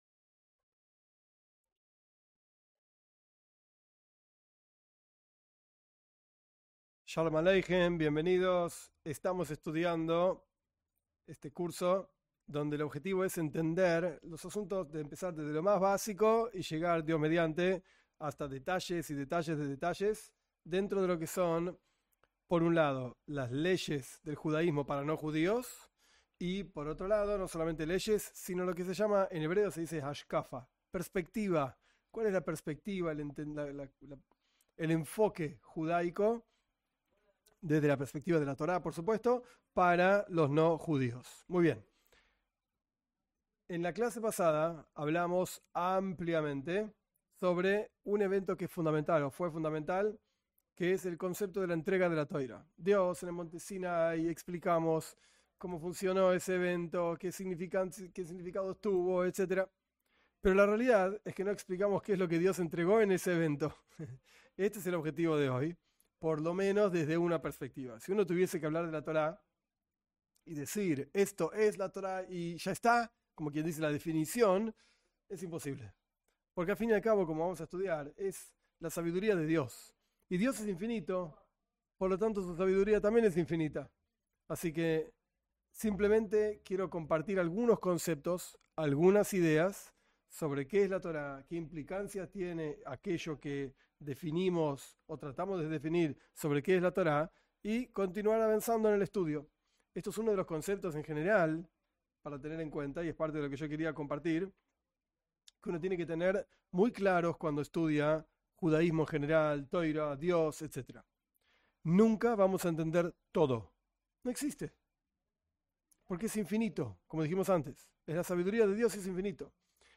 Cada clase tiene un tiempo de preguntas que los participantes preparan de antemano.